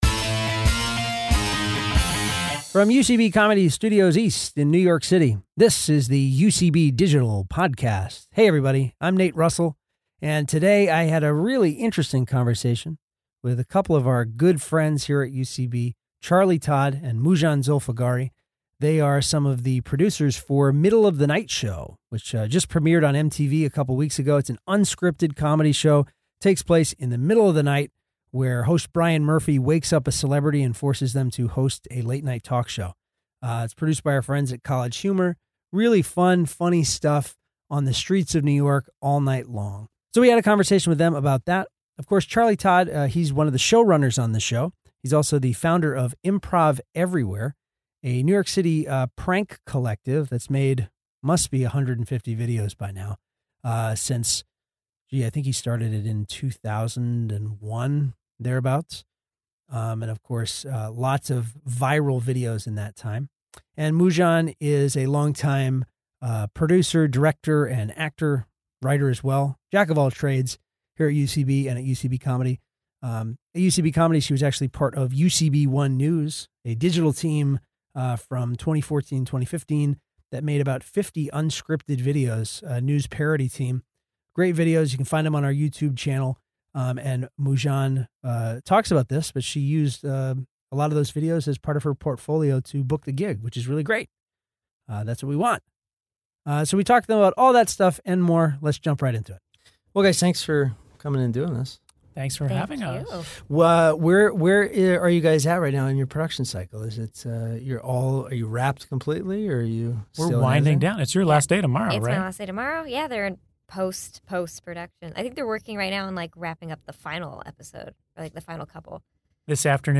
Together, they examine developing the project from a pilot, the trial and error of an unscripted show, the logistics of planning early morning shoots and the challenges of booking celebrity guests. Recorded at UCB Comedy studios in NYC.